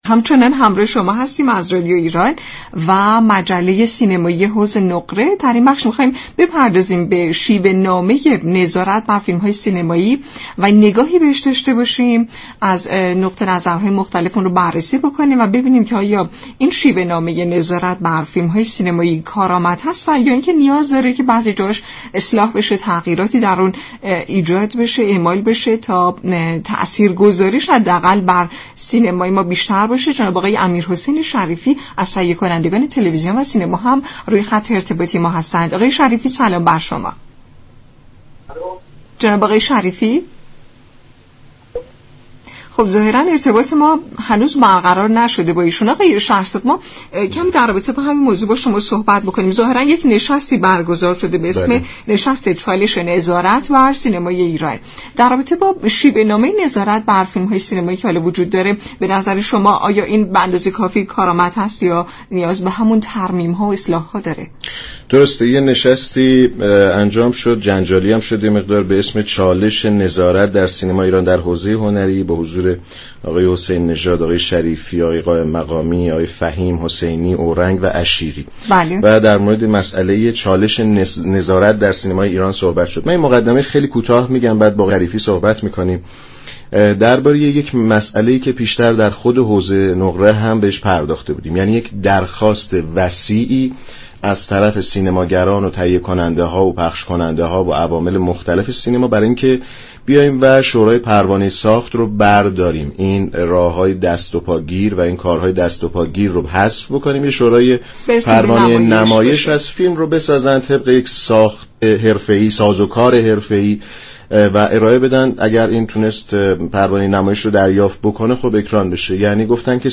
تهیه كننده سینما و تلویزیون در گفت و گو با رادیو ایران گفت: نظارت نباید سلیقه ای اعمال شود؛ روند مافیایی در سینمای ایران باید از میان رود.
برنامه حوض نقره جمعه ها ساعت 17 از رادیو ایران پخش می شود. این گفت و گو را در ادامه باهم می شنویم.